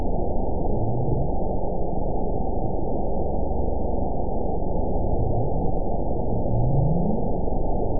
event 920393 date 03/22/24 time 23:16:02 GMT (1 year, 1 month ago) score 9.55 location TSS-AB01 detected by nrw target species NRW annotations +NRW Spectrogram: Frequency (kHz) vs. Time (s) audio not available .wav